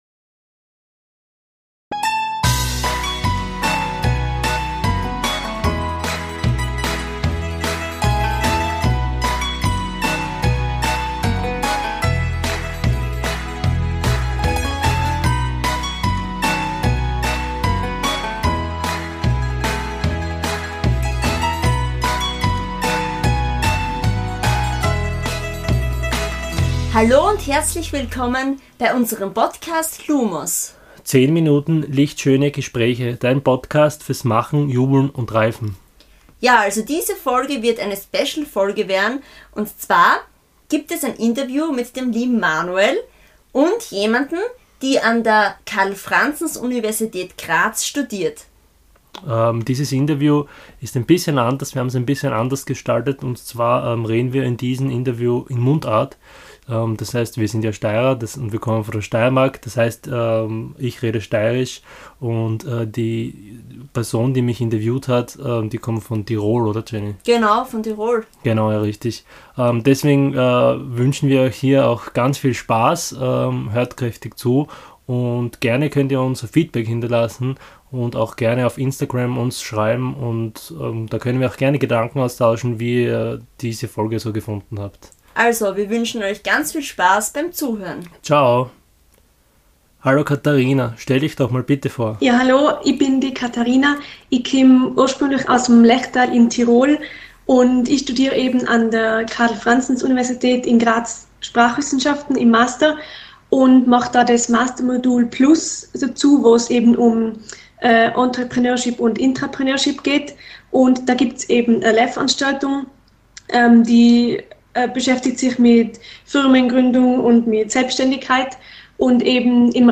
Businessinterviews